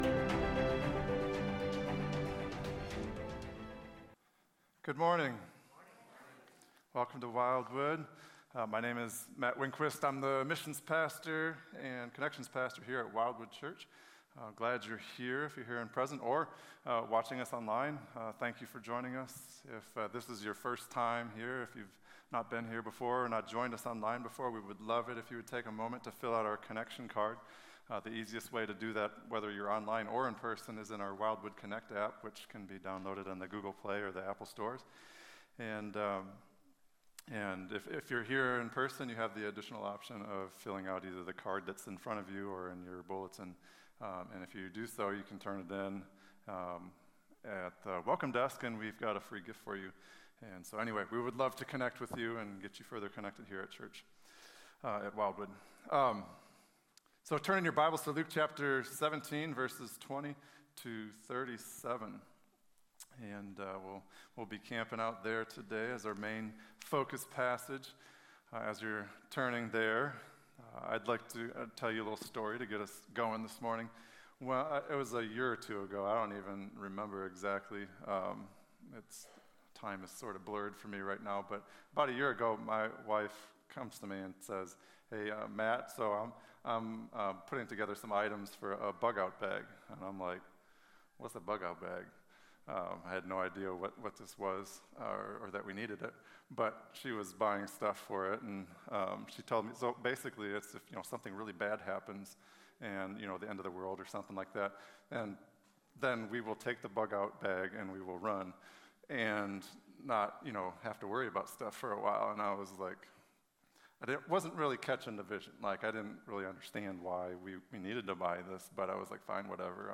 A message from the series "Advent 2020."